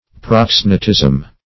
Search Result for " proxenetism" : The Collaborative International Dictionary of English v.0.48: Proxenetism \Prox`e*ne"tism\, n. [Gr.